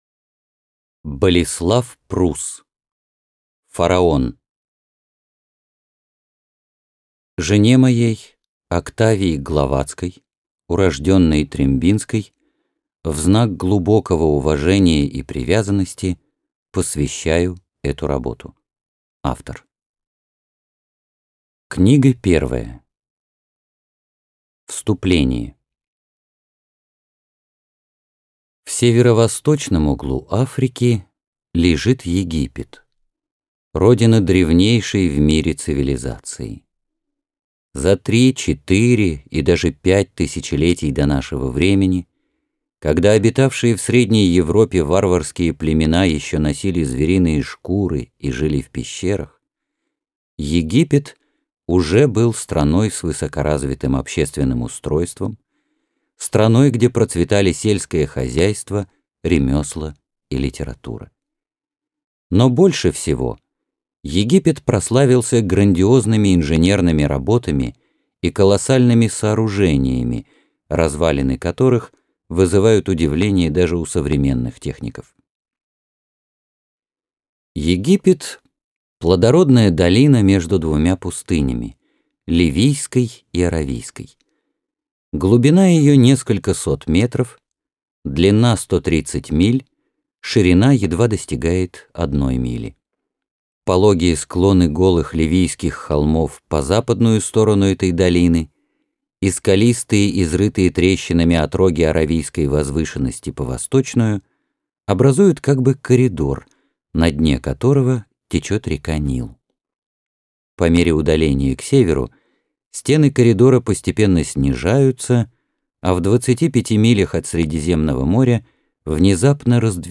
Аудиокнига Фараон | Библиотека аудиокниг